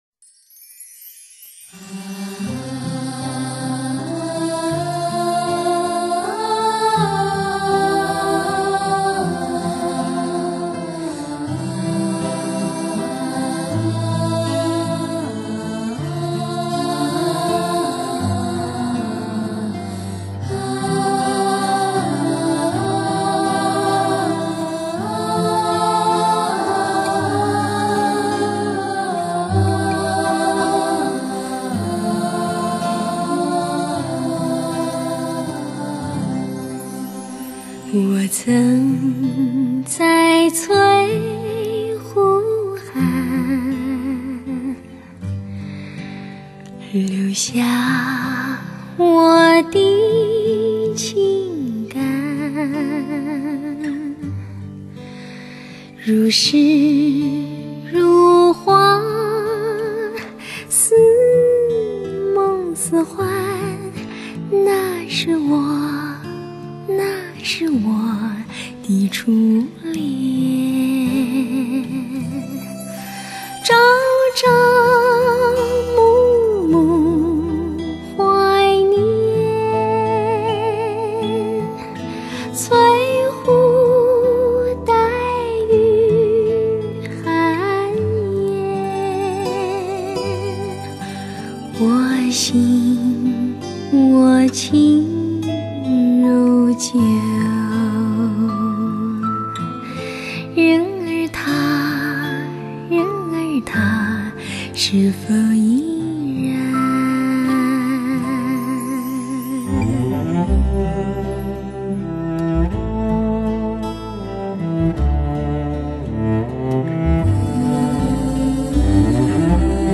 32Bit数码录音之作。
少有的顶级电子管麦克风制作该唱片，女声与伴奏乐器的音色听来有如丝绒般光润甜美，
特别是在够级别的音响组合上更能体会到柔和延伸的超高频，
清新的歌声中露出点点刻骨情怀最是动人，
天碟级的无比通透，圆润人声立体浮现，钢琴的铿锵，二胡的弦等等，
皆出色完美，人声试音首选作品，一句话：可遇不可求！